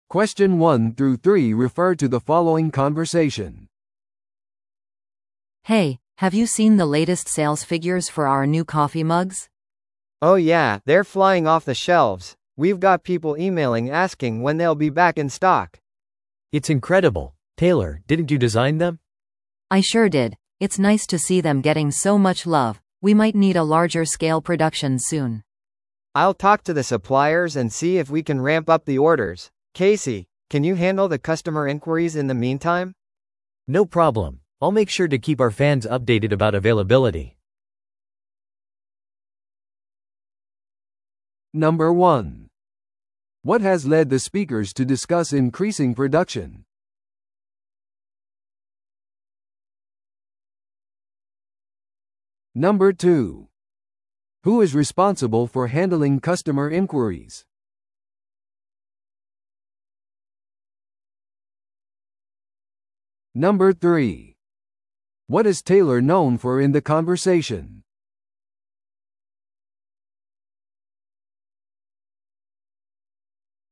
TOEICⓇ対策 Part 3｜新しいコーヒーマグの販売について – 音声付き No.128